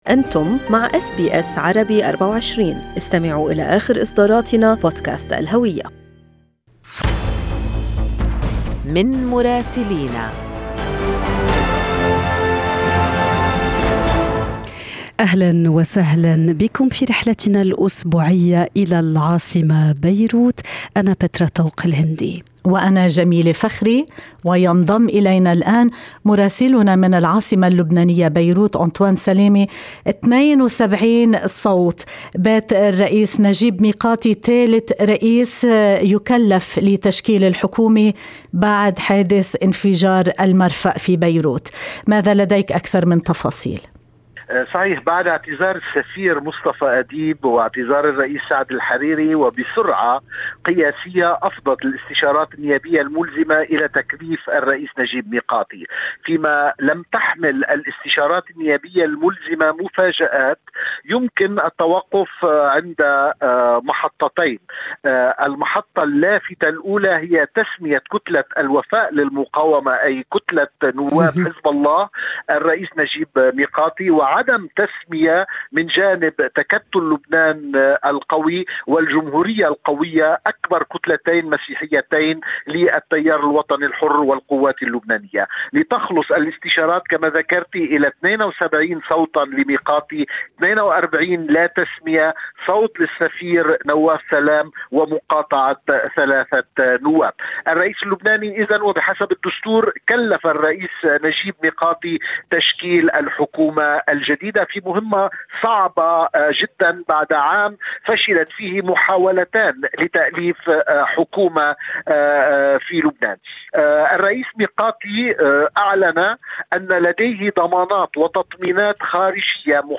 Arabic correspondent